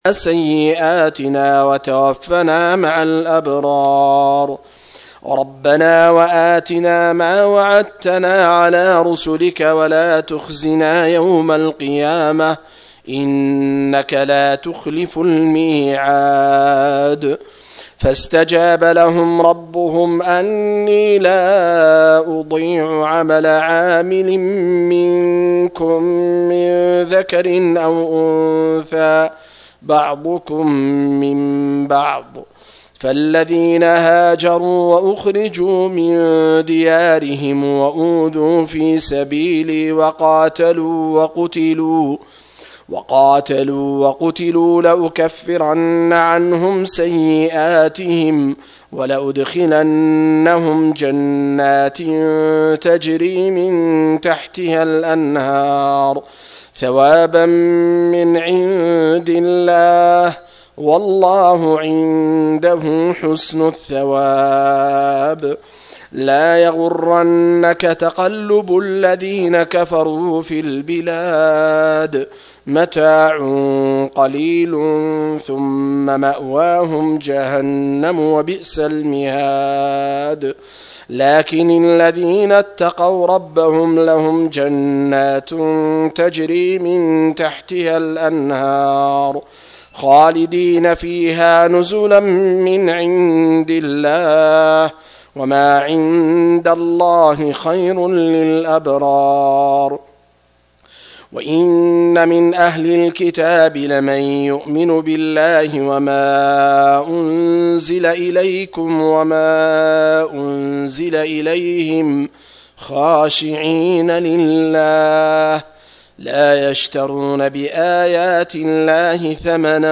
080- عمدة التفسير عن الحافظ ابن كثير رحمه الله للعلامة أحمد شاكر رحمه الله – قراءة وتعليق –